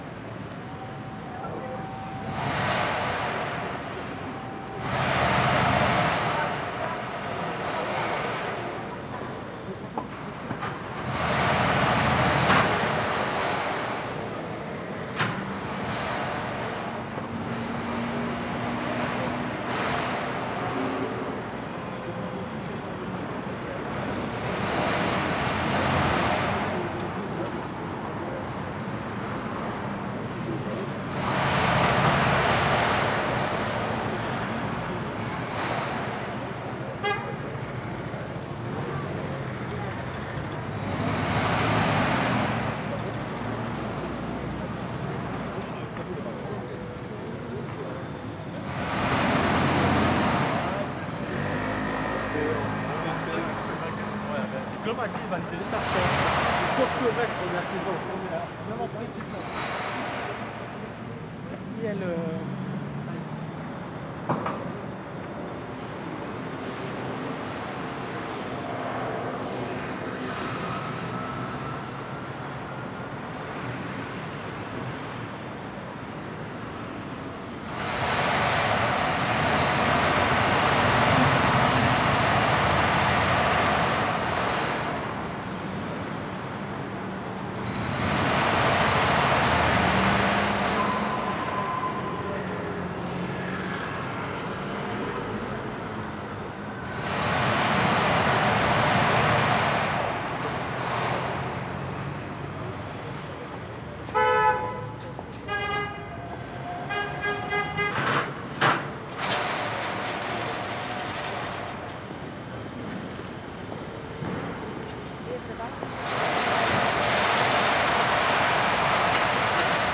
Levallois-Perret crossroads, near the Louise Michel metro station. Traffic and works.